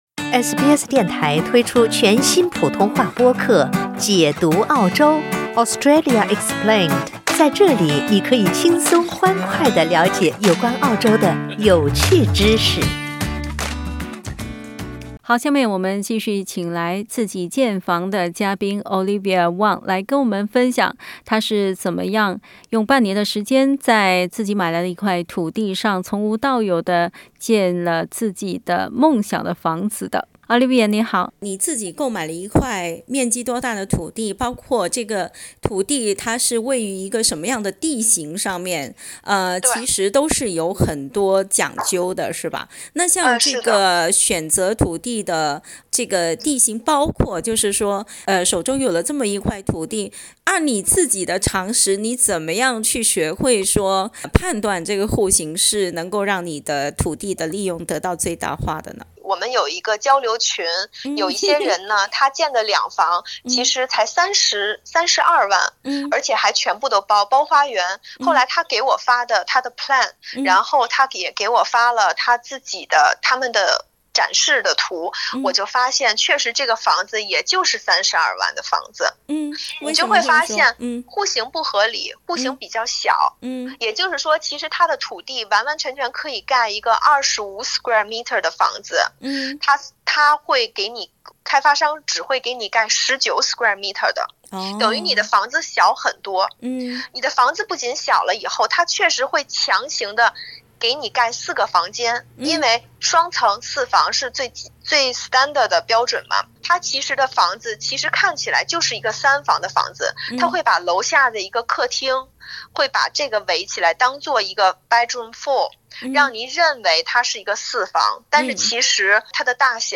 （请听采访，本节目为嘉宾个人体验，仅供参考） 澳大利亚人必须与他人保持至少1.5米的社交距离，请查看您所在州或领地的最新社交限制措施。